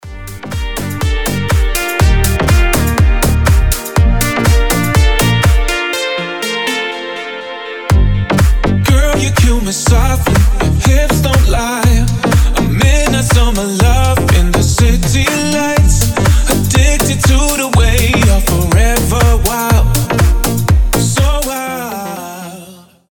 мужской голос
громкие
deep house
nu disco
Indie Dance
Классная летняя музыка